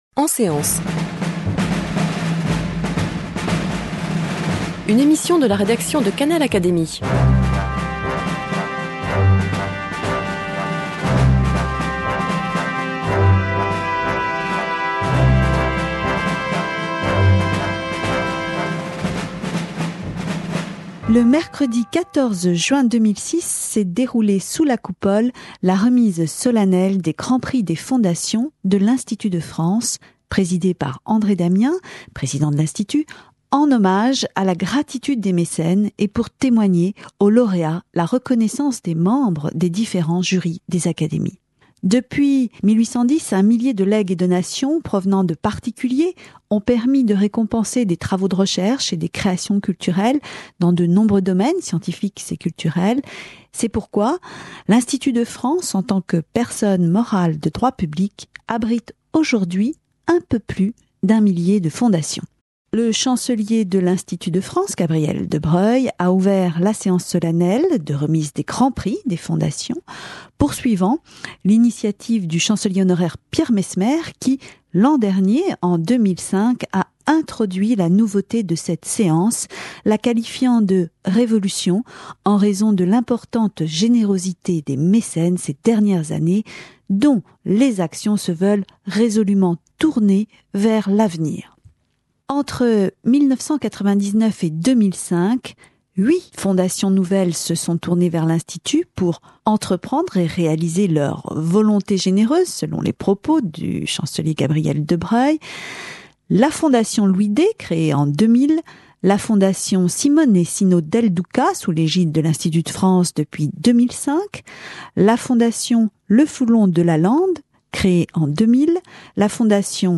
Le mercredi 14 juin 2006, s’est déroulée sous la Coupole, la remise solennelle des Grands Prix des fondations de l’Institut de France, présidée par André Damien, président de l’Institut. Cette cérémonie rend hommage aux mécènes, aux lauréats et au travail des académiciens, membres des différents jurys.
Le chancelier de l'Institut de France, Gabriel de Broglie a ouvert la séance solennelle de remise des Grands Prix des fondations poursuivant l'initiative du chancelier honoraire Pierre Messmer qui, en 2005, a introduit la nouveauté de cette séance, la qualifiant de « révolution » en raison de l'importante générosité des mécènes ces dernières années, dont les actions se veulent résolument tournées vers l'avenir.